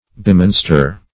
Bemonster \Be*mon"ster\, v. t. To make monstrous or like a monster.